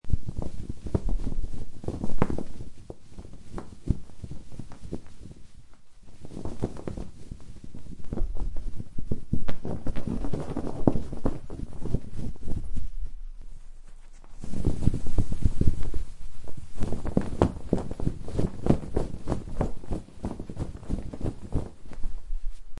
Download Flag sound effect for free.